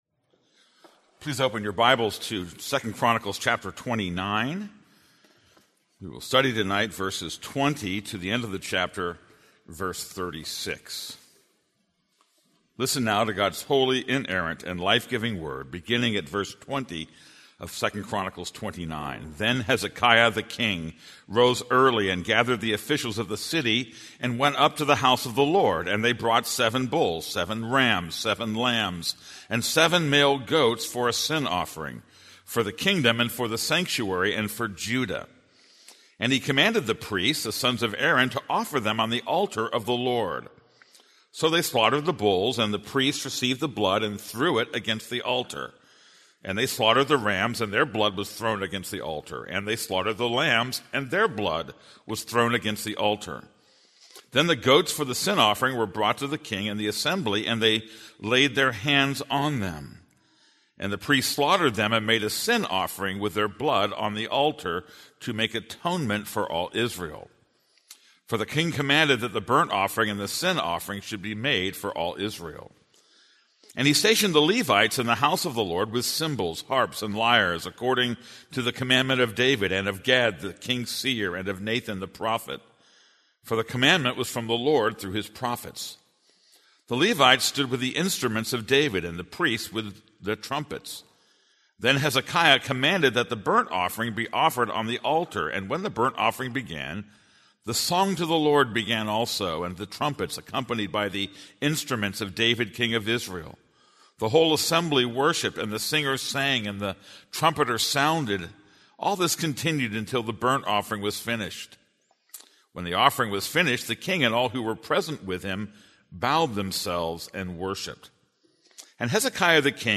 This is a sermon on 2 Chronicles 29:20-36.